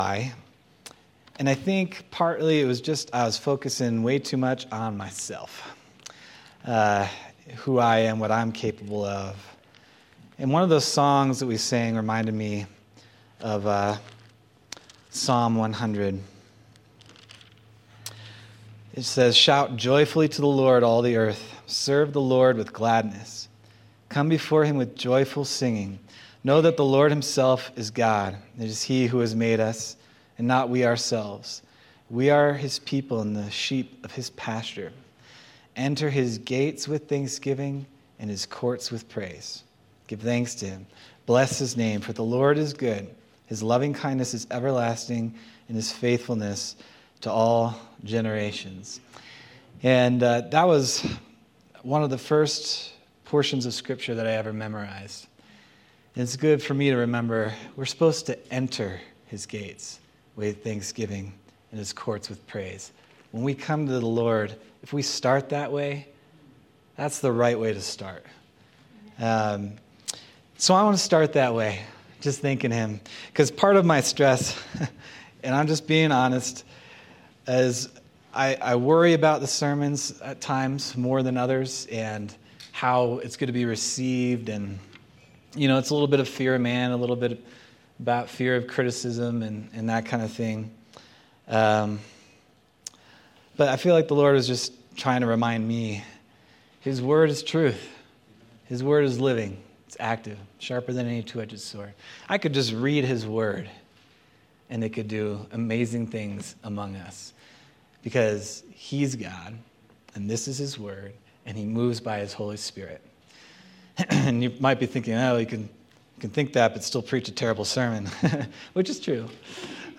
March 16th, 2025 Sermon